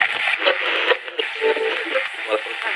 radio_mixdown_2.ogg